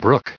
added pronounciation and merriam webster audio
1727_brook.ogg